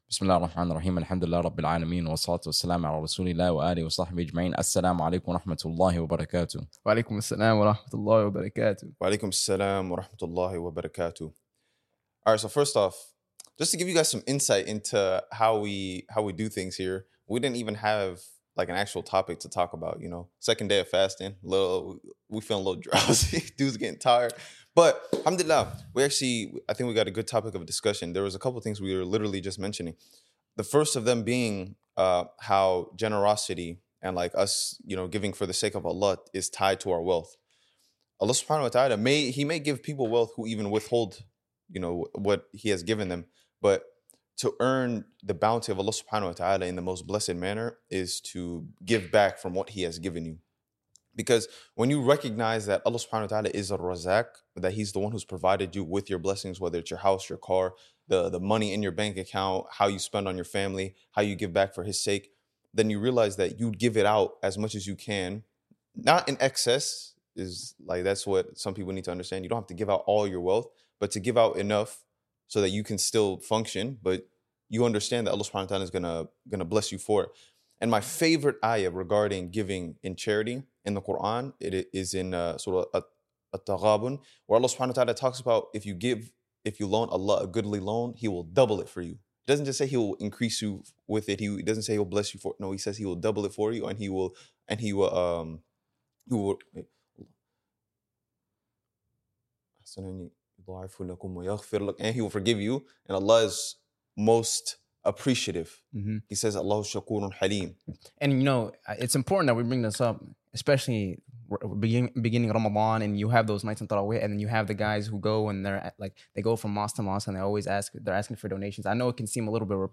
DeenTour is a podcast and channel where 3 brothers showcase their love for islam through reminders, brotherhood, motivation, entertainment, and more!